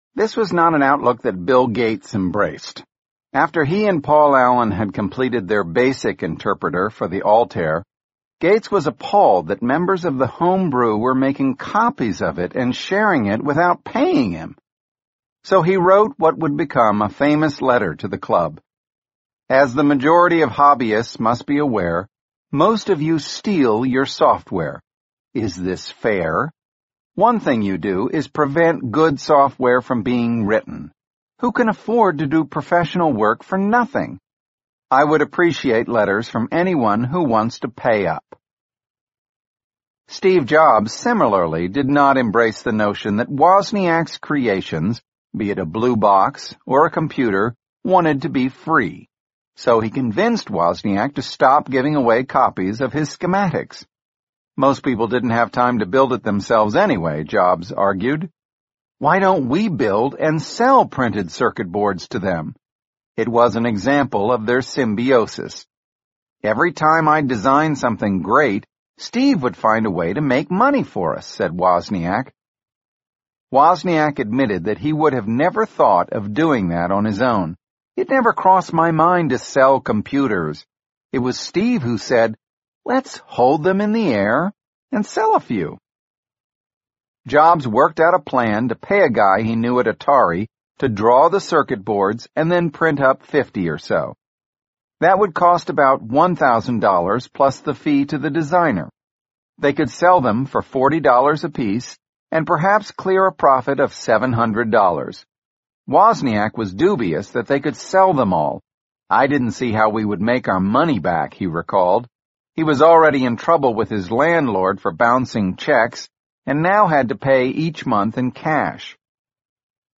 本栏目纯正的英语发音，以及完整的传记内容，详细描述了乔布斯的一生，是学习英语的必备材料。